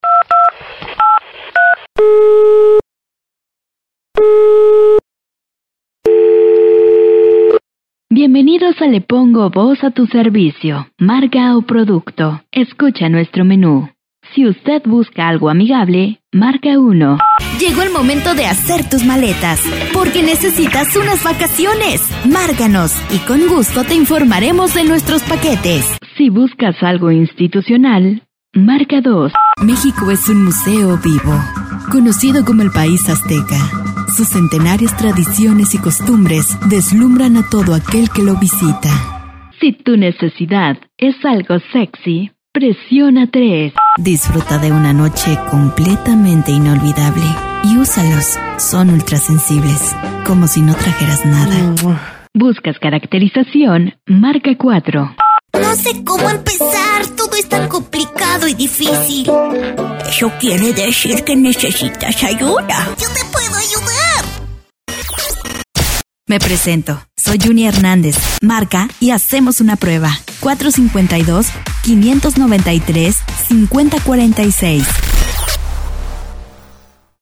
Tengo 7 años haciendo locucion Comercial.
Soy Originaria de México, estoy a sus ordenes para darle voz a tu producto, marca, o evento,
Sprechprobe: Werbung (Muttersprache):